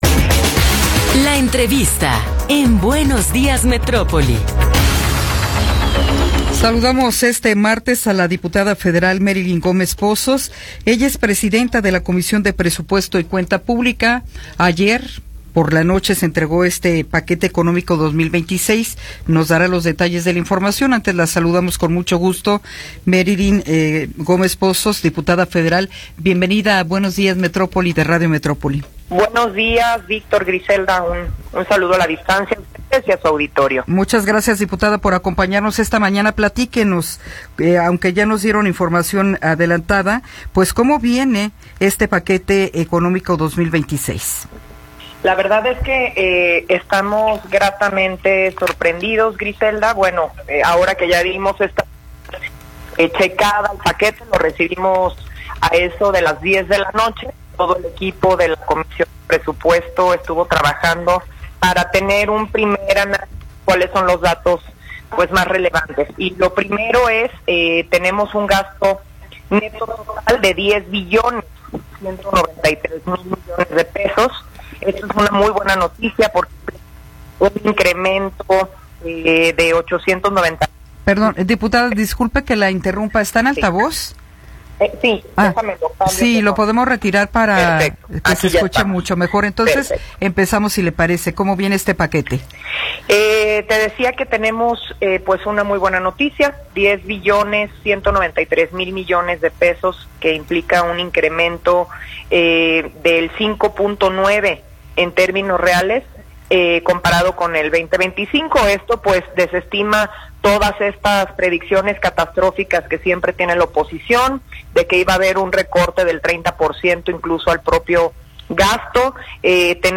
Entrevista con Merilyn Gómez Pozos